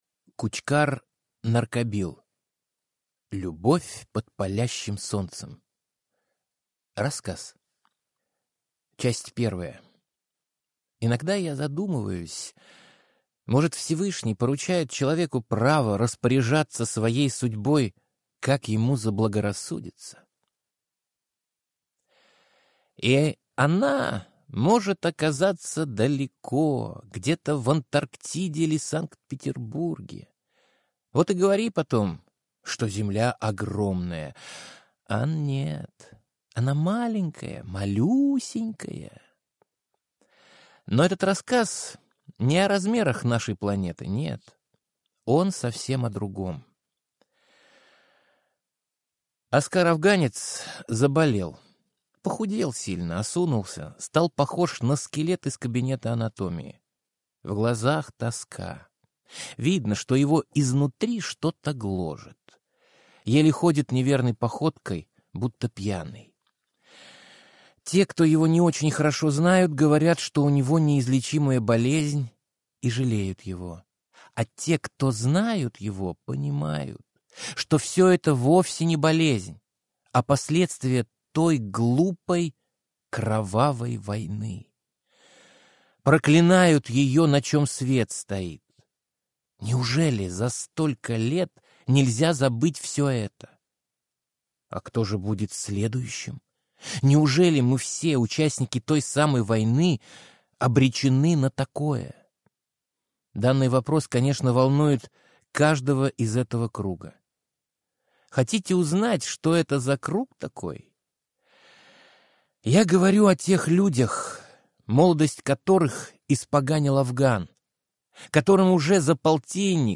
Аудиокнига Любовь под палящим солнцем | Библиотека аудиокниг